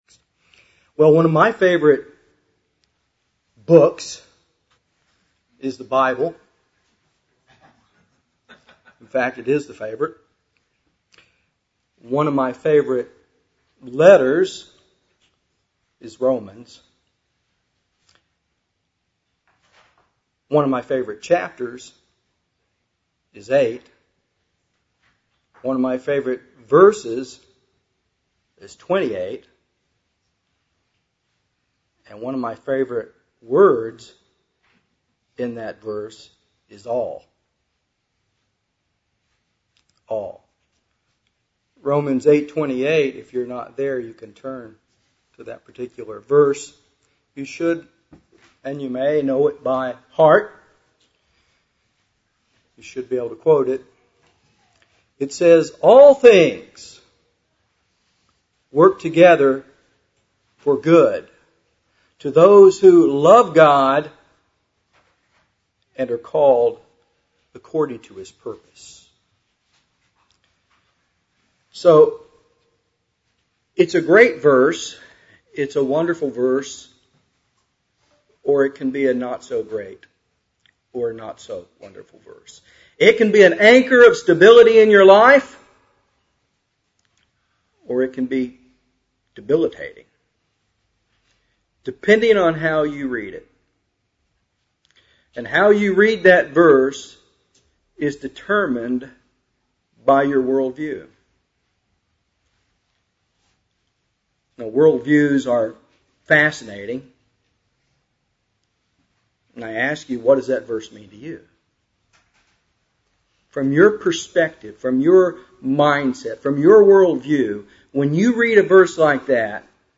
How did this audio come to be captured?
Given in Dallas, TX Fort Worth, TX